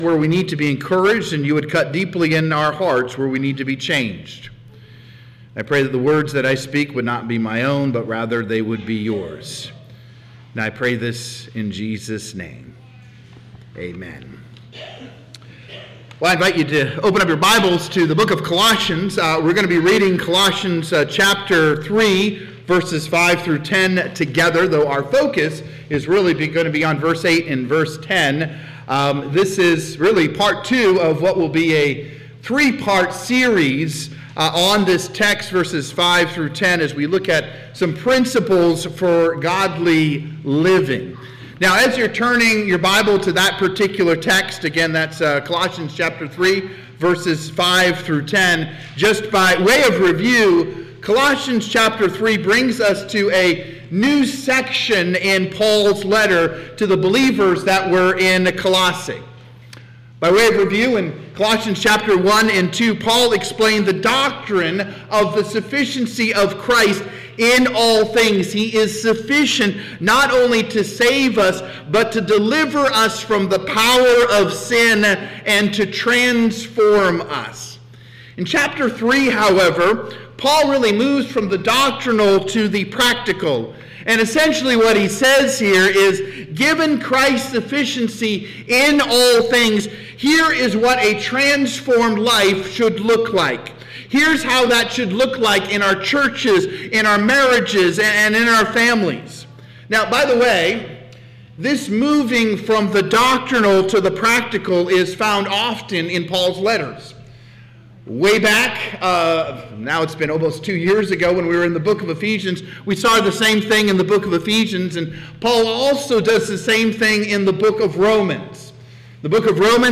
Worship | Broken for Christ
Music, Prayer, Scripture, Sermons